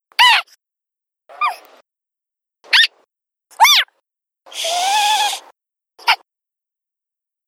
whackaMole_randomSqueak_2.wav